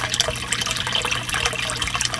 trickle1.ogg